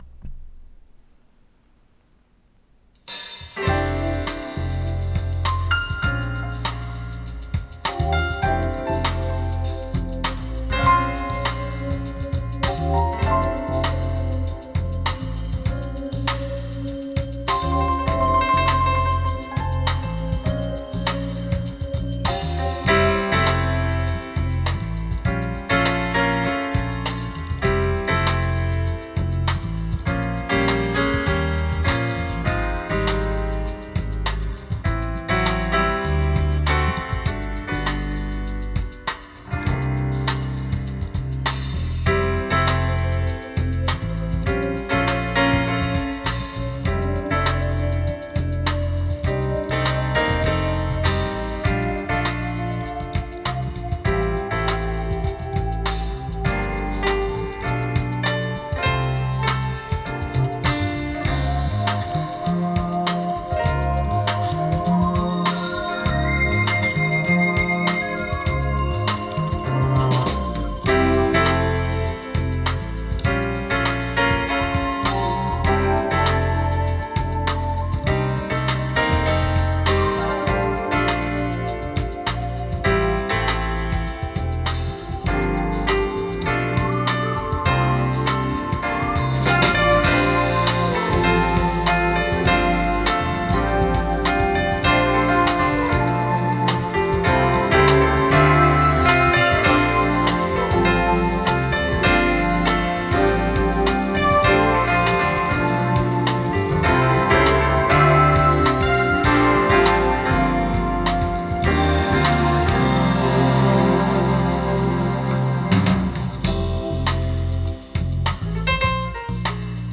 もうかれこれ１５年以上経つので、なんか左右の音量差があります。
録音した音は、かなりライブな感じ（反響音が大きい？）がします。
項目 構成 録音ファイル＆感想
かなりフラットな特性だと思います。
高音の伸びもいいです。